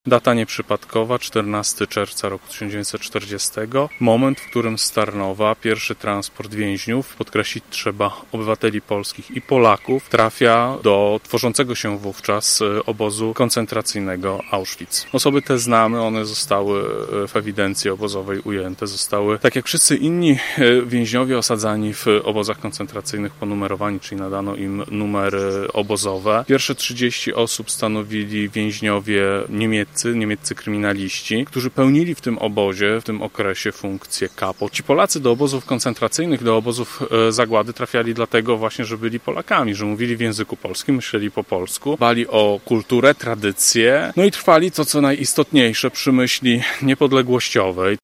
Wojewoda lubuski Władysław Dajczak podczas uroczystości przy pomniku 16 ofiar tamtych wydarzeń, ofiar zmarłych w Gorzowie z obozu w Ravensbruck, mówił o pamięci o tych ludziach i zbrodniach nazistów.